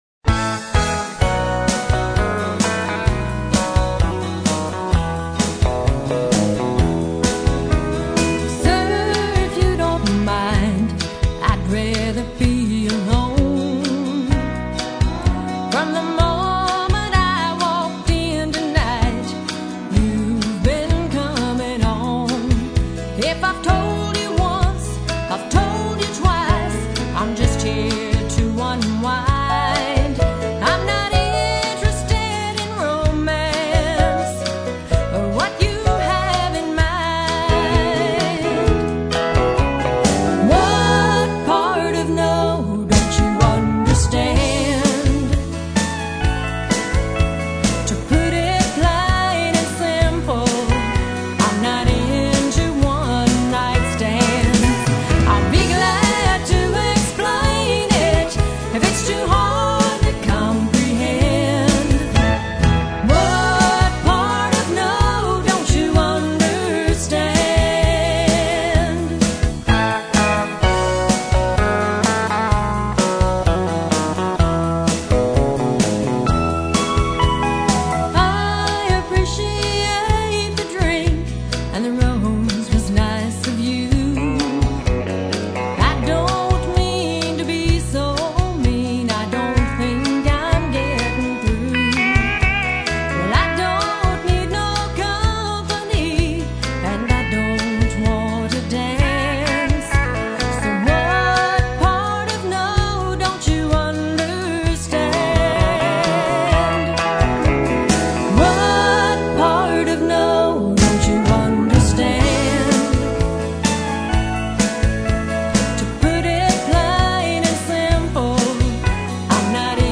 Classic country songs such as Fraulein